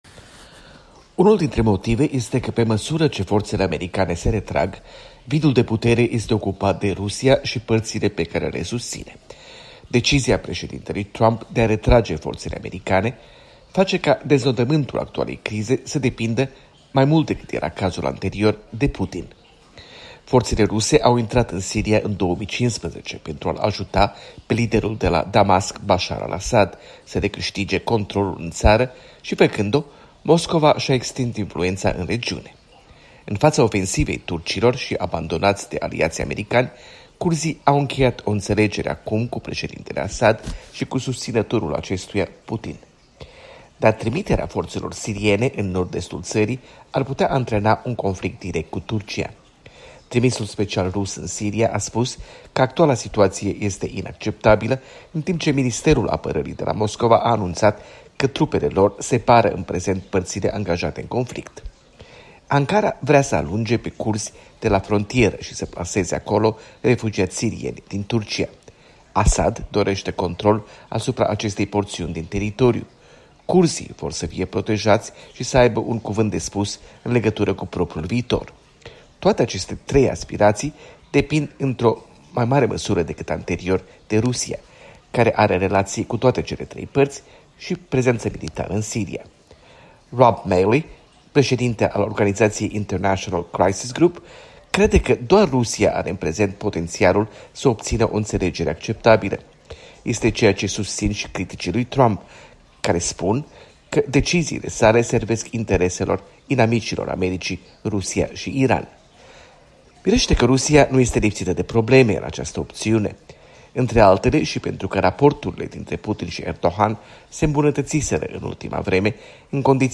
Rusia - Siria, o corespondență de la Washington